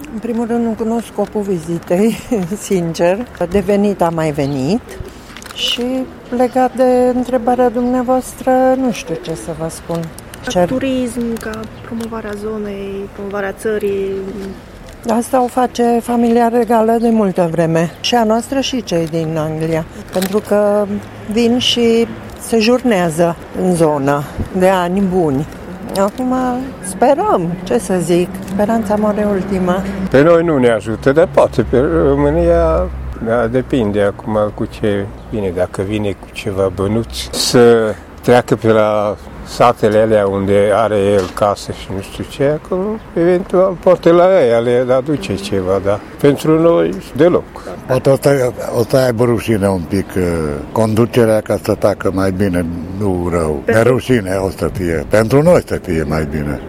Chiar dacă sunt mândri că moștenitorul Coroanei britanice a trecut județul Mureș pe lista obiectivelor vizitate, tîrgumureșenii spun că aceste vizite aduc prea puțin pentru țară și români: